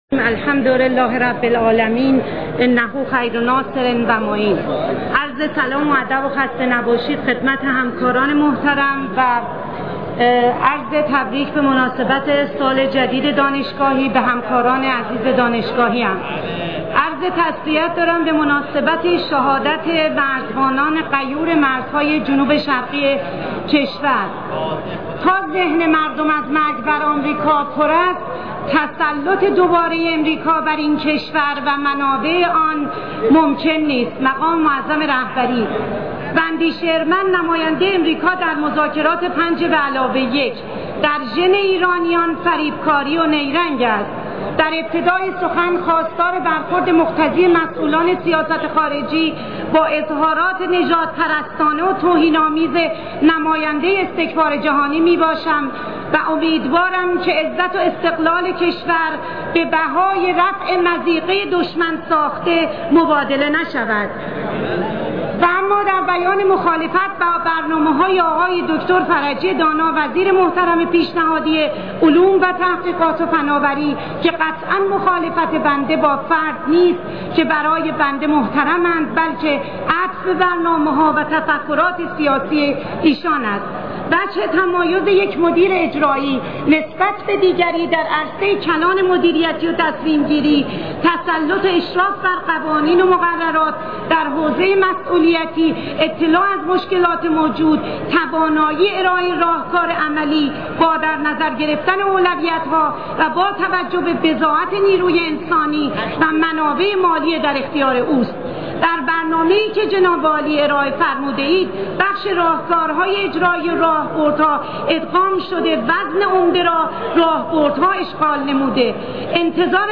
به گزارش «نماینده» به نقل از فارس، زهره طبیب‌زاده نماینده مردم تهران در مجلس شورای اسلامی در جلسه علنی صبح امروز (یکشنبه) خانه ملت در سخنانی به عنوان دومین مخالف رضا فرجی دانا وزیر پیشنهادی علوم، تحقیقات و فناوری، برنامه‌های فرجی دانا را ایده‌آلیستی خواند و اظهار داشت: این برنامه‌ها عملی و کاربردی نیست، ارائه این برنامه‌ها از سوی فرجی دانا نمایانگر عدم اشراف به امکانات و منابع کشور و منابع وزارت علوم است.